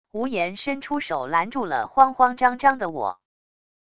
吴言伸出手拦住了慌慌张张的我wav音频生成系统WAV Audio Player